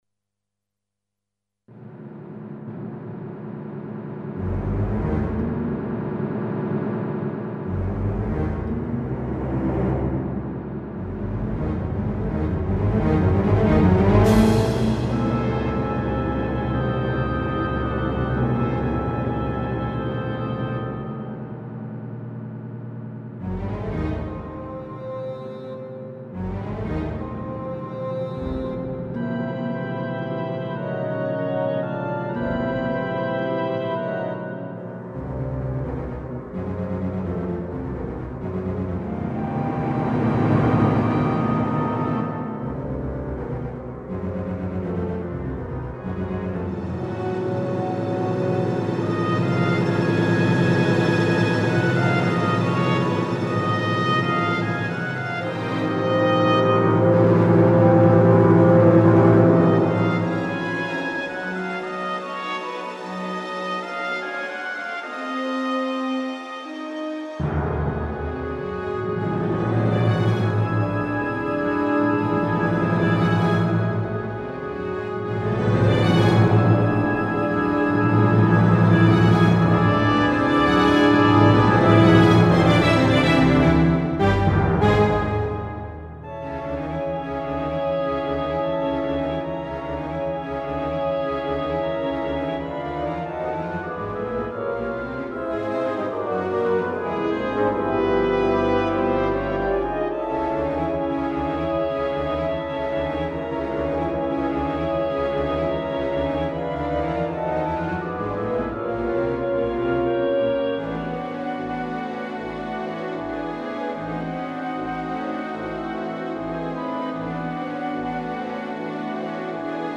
I made it with :Miroslav Philharmonik"Claude Debussy (1862 - 1918)